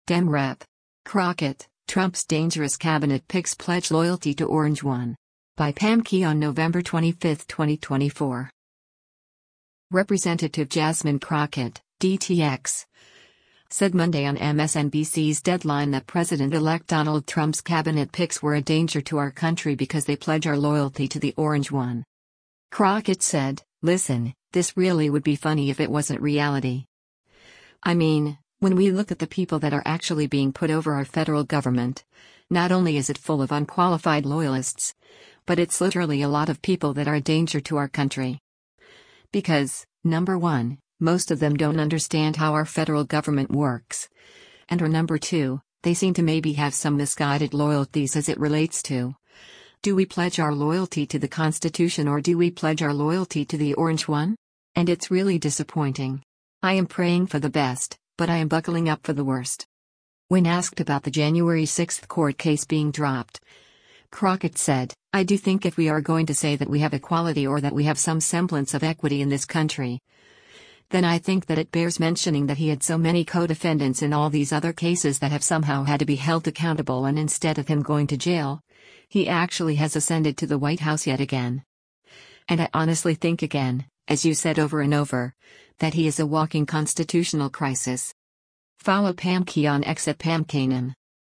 Representative Jasmine Crockett (D-TX) said Monday on MSNBC’s “Deadline” that President-elect Donald Trump’s cabinet picks were a “danger to our country” because they pledge our loyalty to the “orange one.”